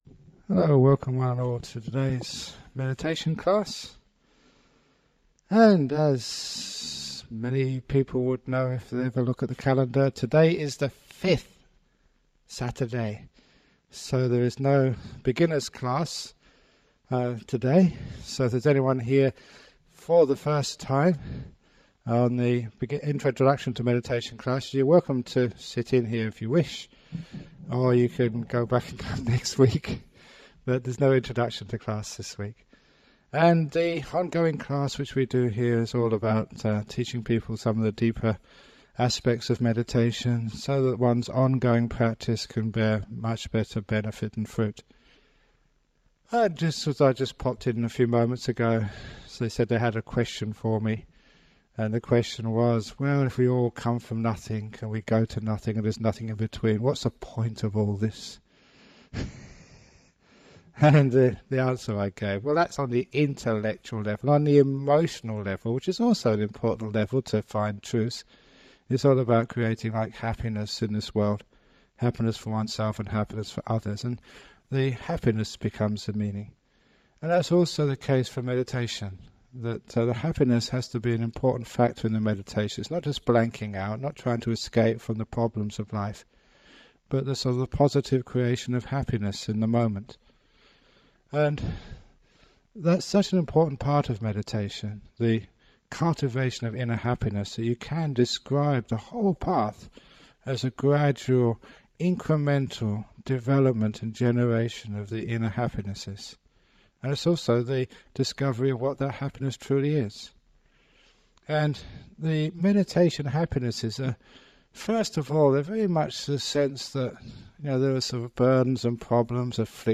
It includes a talk about some aspect of meditation followed by a 45 minute guided meditation. This guided meditation has been remastered and published by the Everyday Dhamma Network, and will be of interest to people who have started meditation but are seeking guidance to take it deeper.